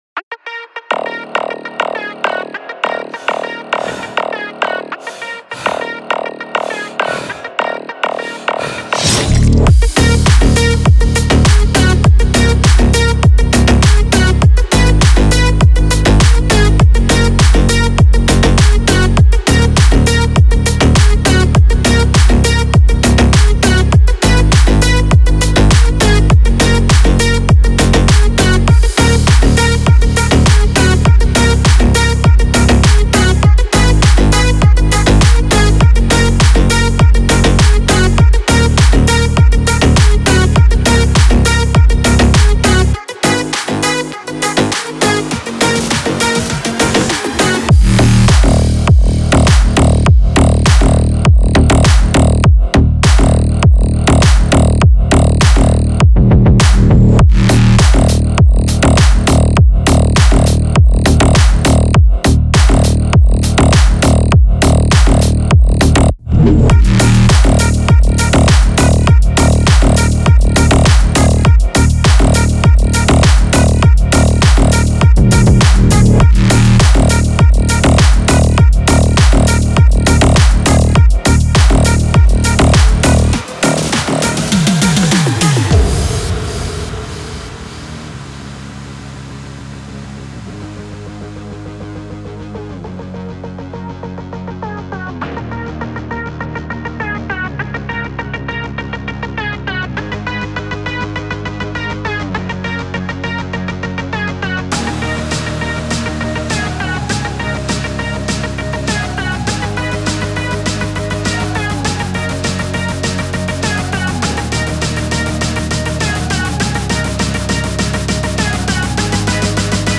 КЛУБНЯК в МАШИНУ